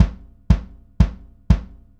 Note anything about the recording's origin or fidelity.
For illustration purposes; supposing this is an official recording of a kick drum track that you want to be quality-controlled using MD5 checksum.